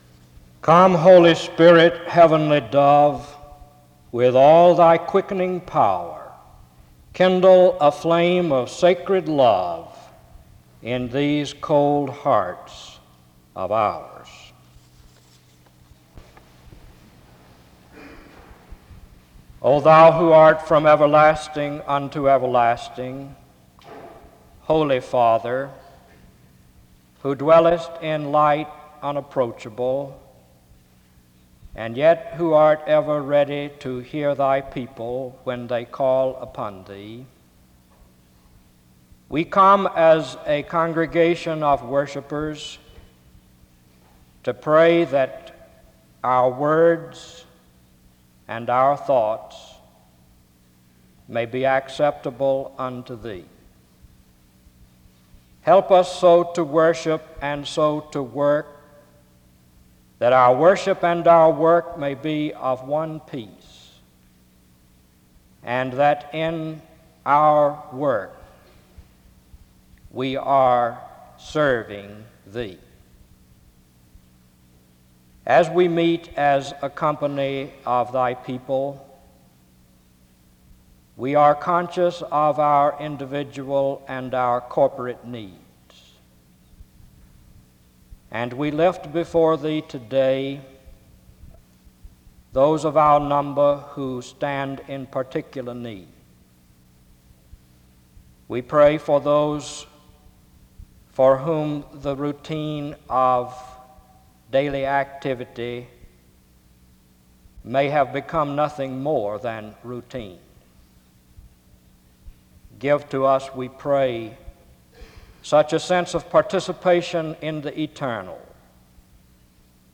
Resource type Audio Citation Archives and Special Collections, Library at Southeastern, Southeastern Baptist Theological Seminary, Wake Forest, NC.
SEBTS Chapel and Special Event Recordings SEBTS Chapel and Special Event Recordings